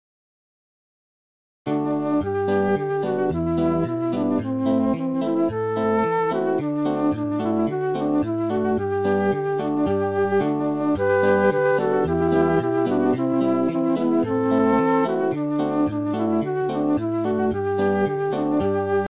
Вокзальный автоинформатор г. Чапаевска